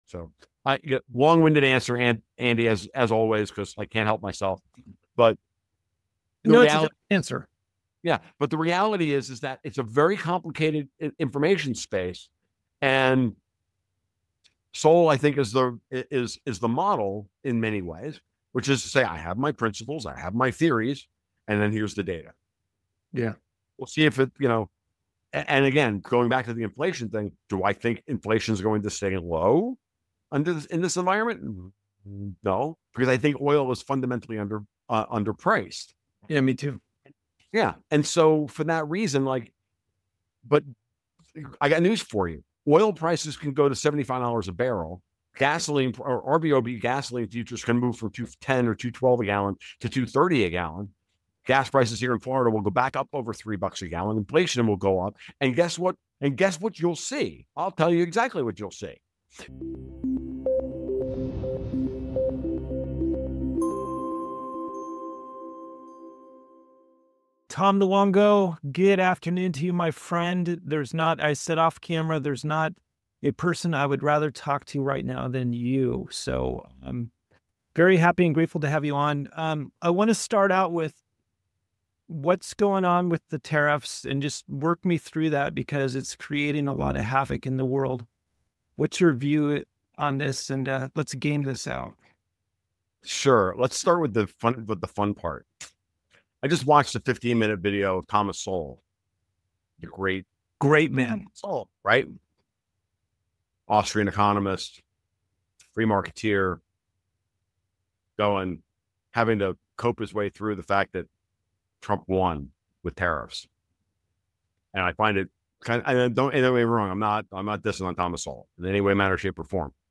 in-depth discussion